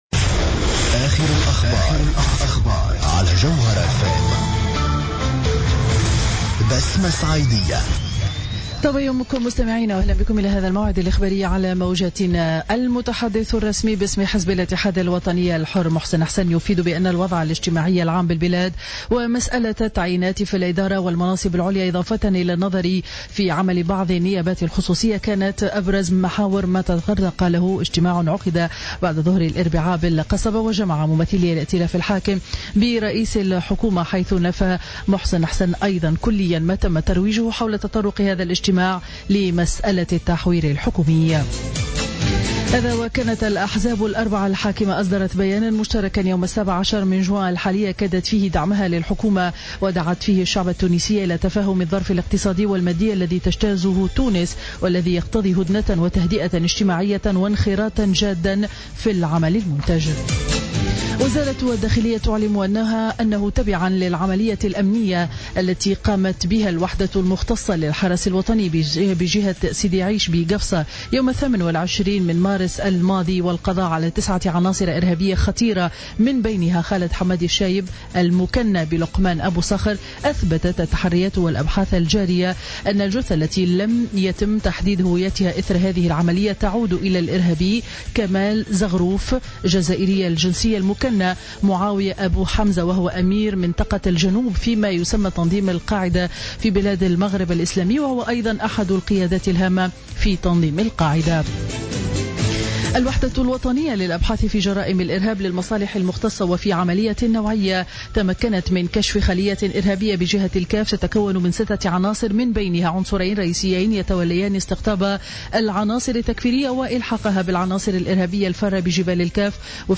نشرة أخبار السابعة صباحا ليوم الخميس 25 جوان 2015